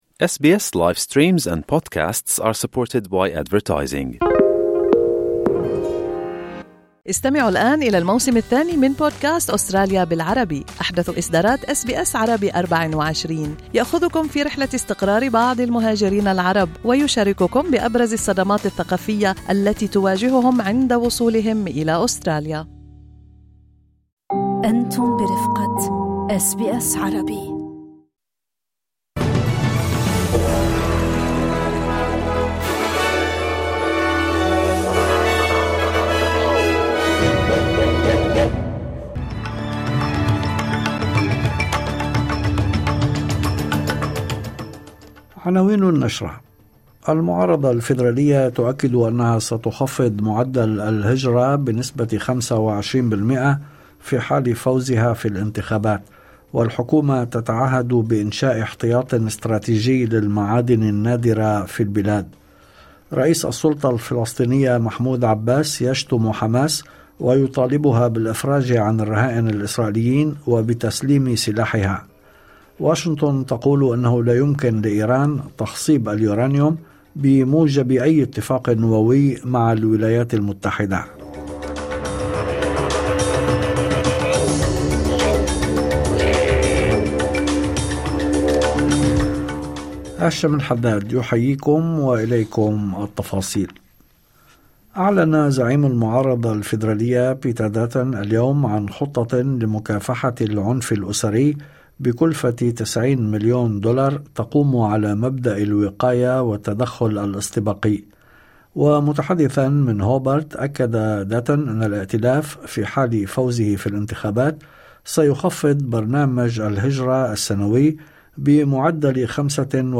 نشرة أخبار المساء 24/04/2025